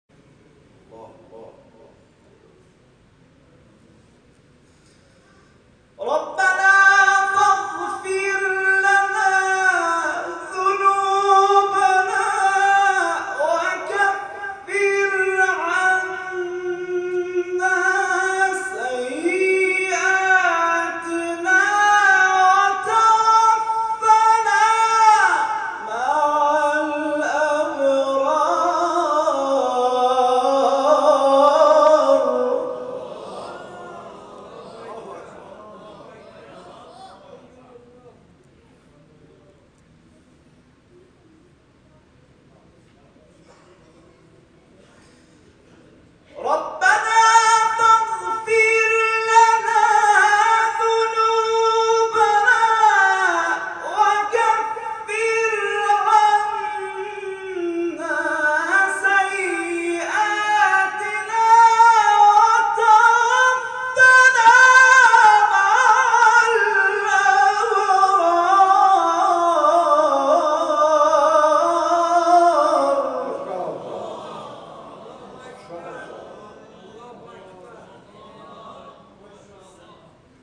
نغمات صوتی از قاریان ممتاز کشور